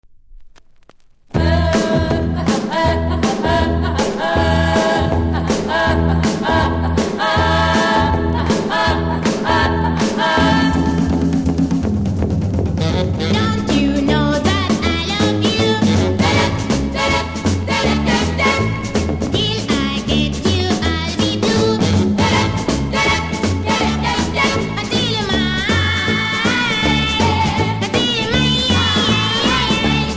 キズ多めで見た目悪いですが音は良好なので試聴で確認下さい。